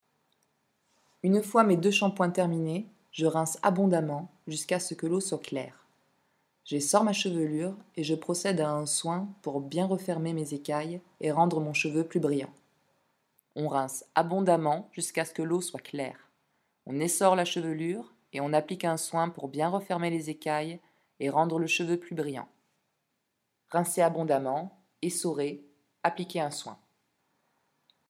Installation sonore, boucle 13’11’ ‘, 2013.
La pièce met à disposition trois textes énoncés par une même voix féminine sans lien avec le métier de coiffeuse.